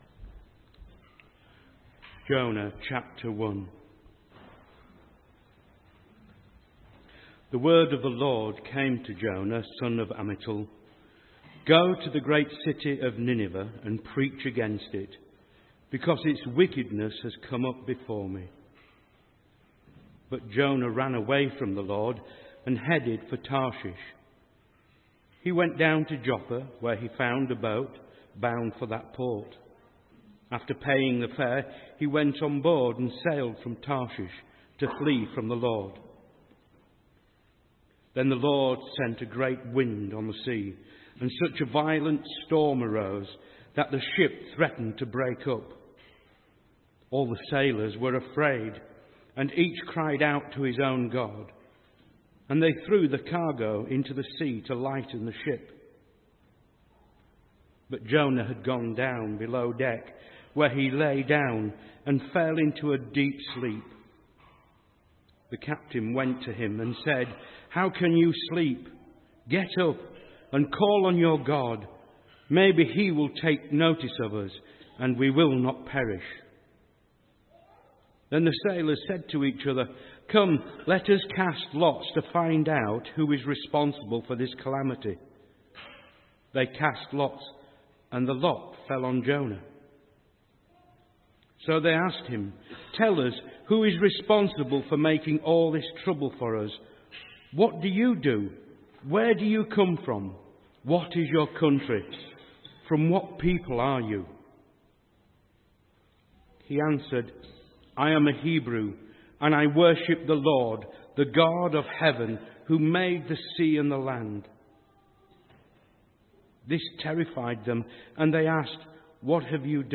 A message from the series
From Service: "10.45am Service"